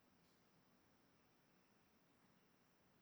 Mountain River Hiss. Best way to remove?
I’m recording in a natural environment where there is a river about 500 meters away. This is a sample of the hiss sound I’m picking up.
If it matters, I’m using Audacity 2.3.2, a Focusrite Scarlet 2i2, and a FiveO Montarbo PM-85 dynamic mic.
It’s spectrum & low volume (<-60dB) lead me to suspect that hiss is the inherent noise of the electronics,rather than a river.